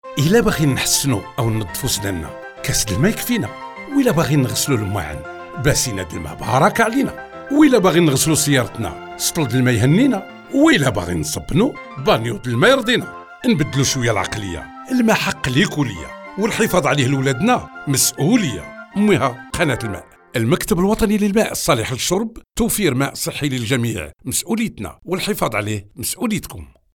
Spots radio:
onep-Radio brosser les dents 27 sec.mp3